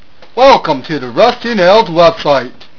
Said lovingly